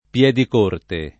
[ p LH dik 1 rte ]